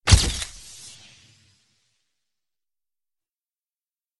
Звуки вспышки света
В коллекции представлены как резкие импульсные звуки, так и мягкие световые эффекты.
Яркая вспышка камеры